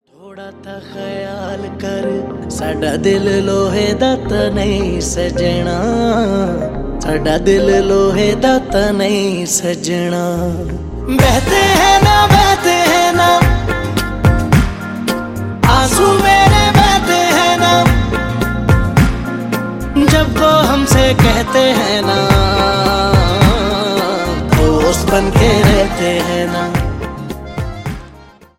is a soul-stirring melody